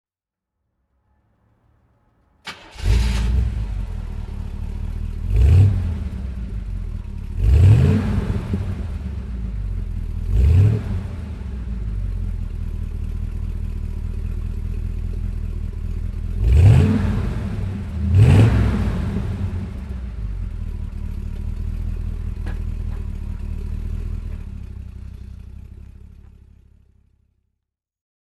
Bitter CD (1978) - Starten und Leerlauf